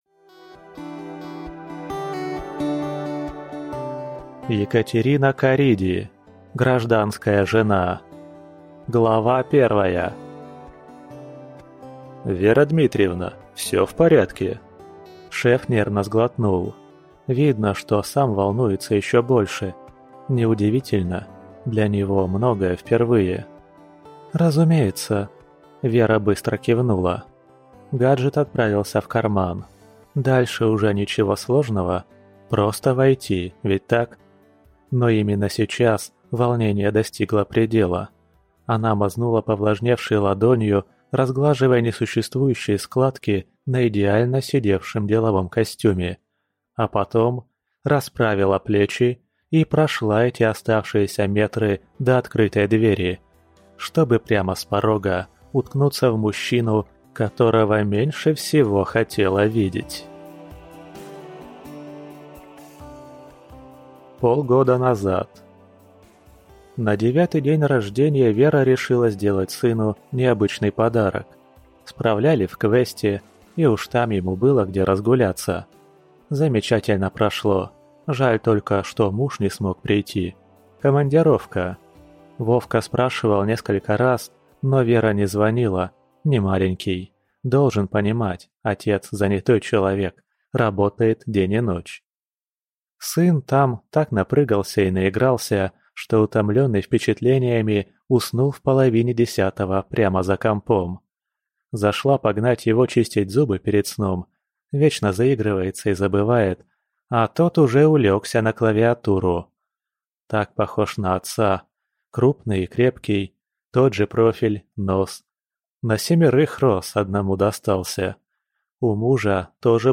Аудиокнига Гражданская жена | Библиотека аудиокниг